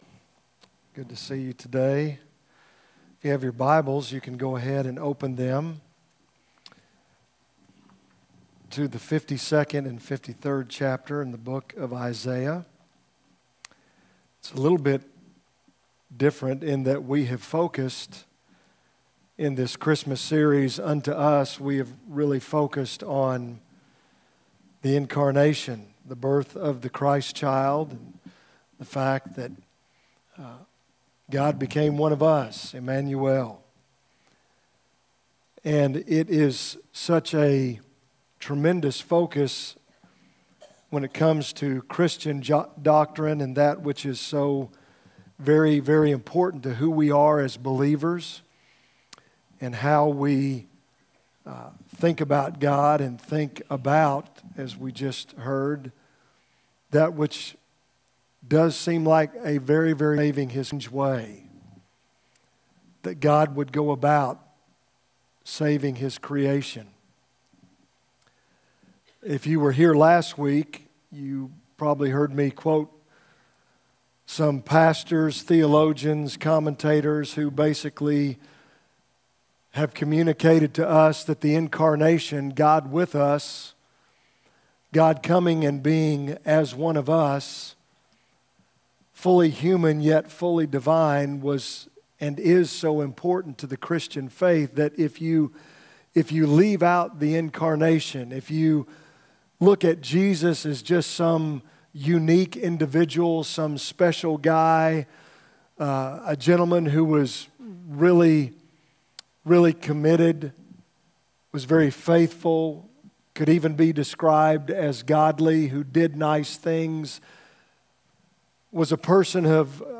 A message from the series "For Unto Us." Our final week of our 2018 Christmas series - For Unto Us